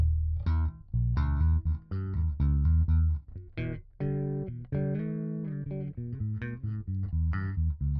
Quick recordings, rusty playing of 6 strings Harley Benton bass, passive mode.